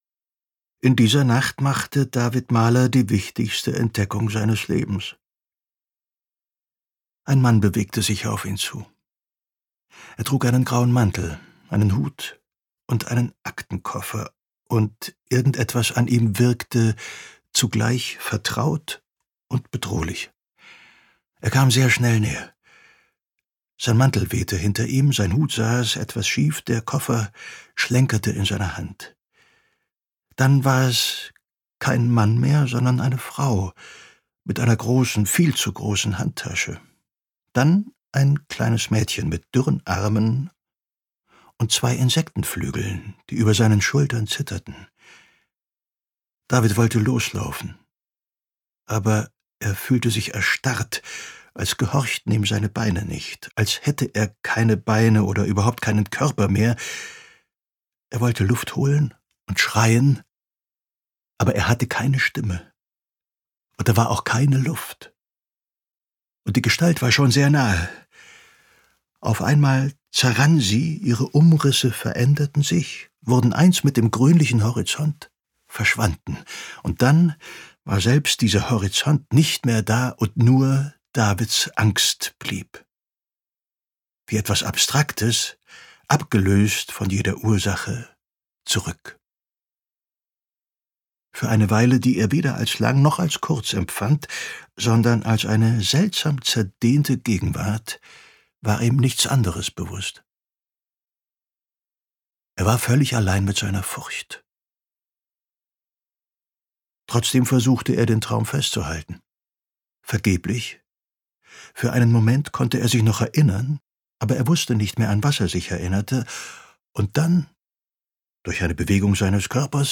Gekürzt Autorisierte, d.h. von Autor:innen und / oder Verlagen freigegebene, bearbeitete Fassung.
Mahlers Zeit Gelesen von: Ulrich Noethen
Ulrich Noethen gehört zu den vielseitigsten und beliebtesten Schauspielern Deutschlands. Sein warmes, dunkl ...